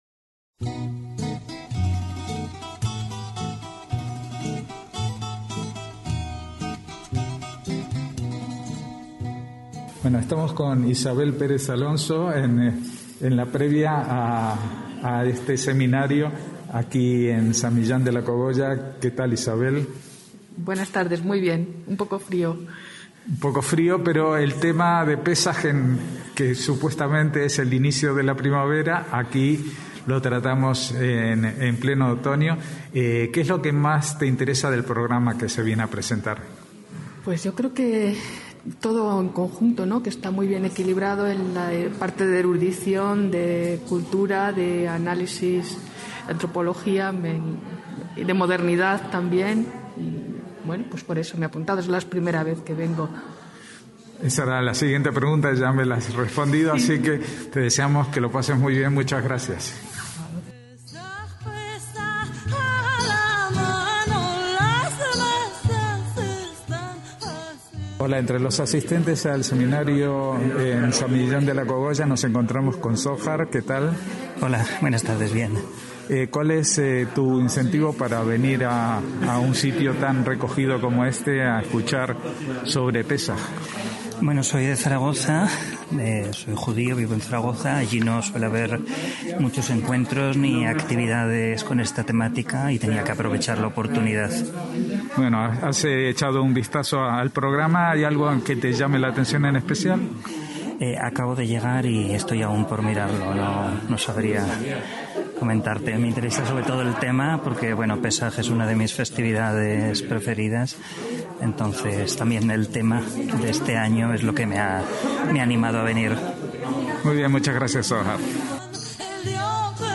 Radio Sefarad asistió a las XIª edición de las Jornadas Sefardíes que cada dos años se celebran en San Millán de la Cogolla, y estuvimos tomando el pulso de las expectativas previas de los asistentes, así como de la inauguración de la exposición en torno al tema central de esta edición de las Jornadas, la fiesta de Pésaj, bajo el título "La mesa y el relato"